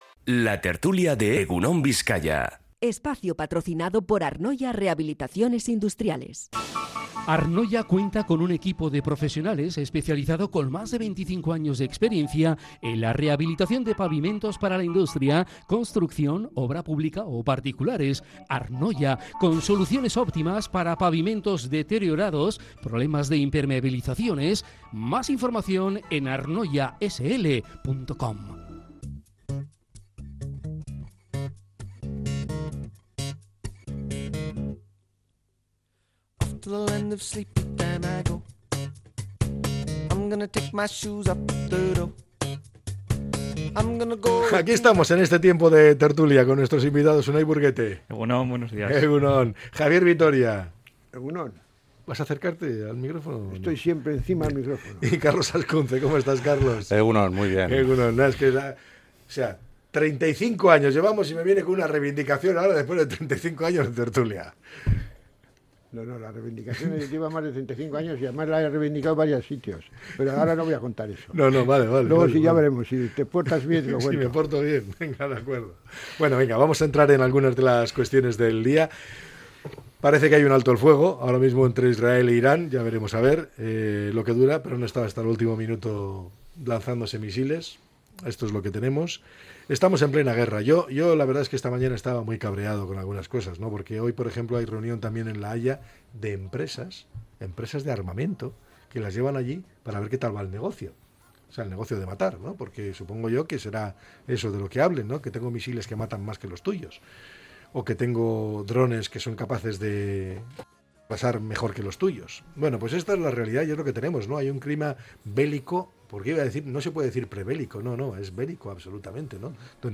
La tertulia 24-06-25.